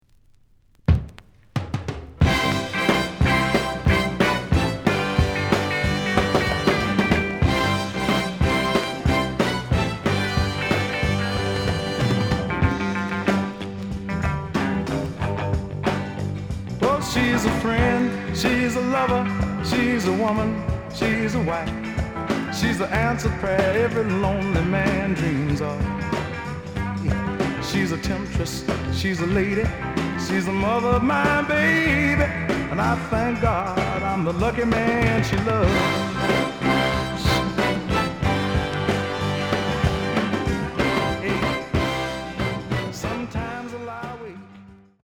The listen sample is recorded from the actual item.
●Genre: Soul, 60's Soul
Some click noise on beginning of A side due to scratches.